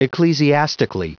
Prononciation du mot ecclesiastically en anglais (fichier audio)
ecclesiastically.wav